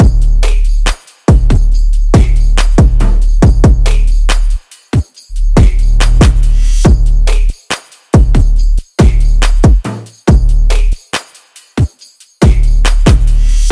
Full Phonk Drum Sequence - Am 140.ogg
Hard punchy drums sample for Memphis Phonk/ Hip Hop and Trap like sound.